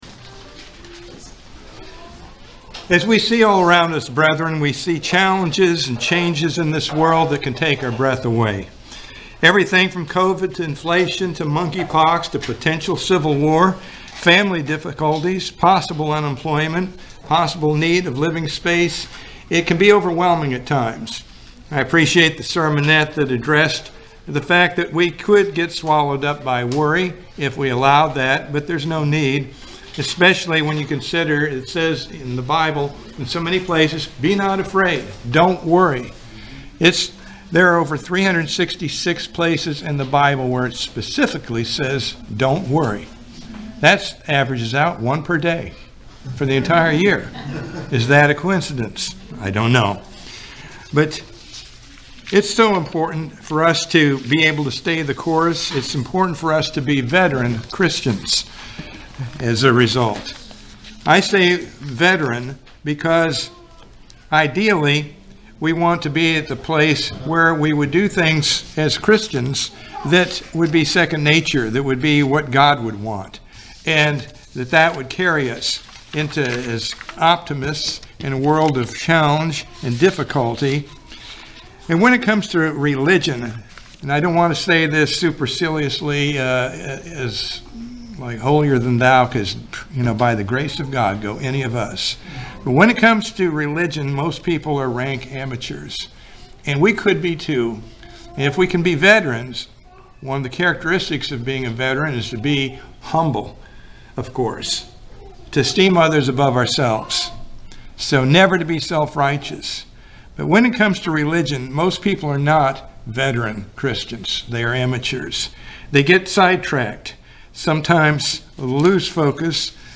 This sermon identifies several defining differences between amateurs and veterans that we need to be reminded of.
Given in Ocala, FL